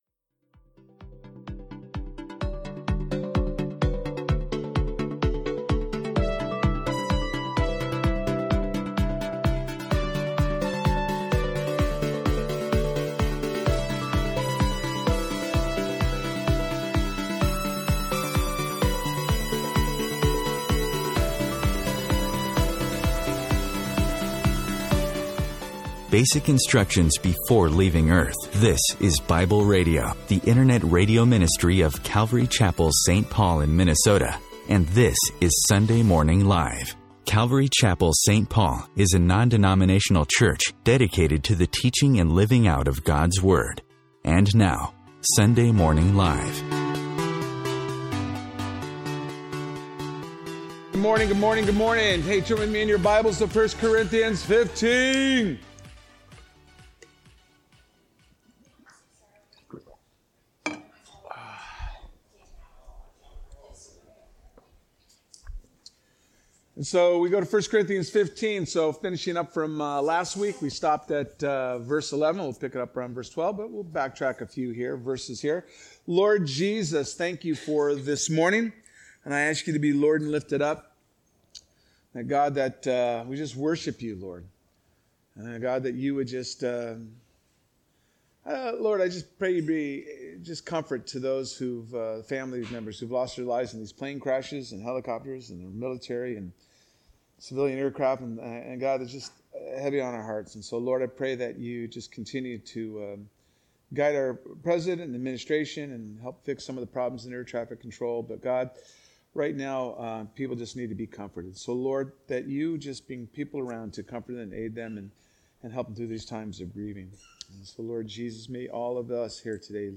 A message from the series "3000 Series."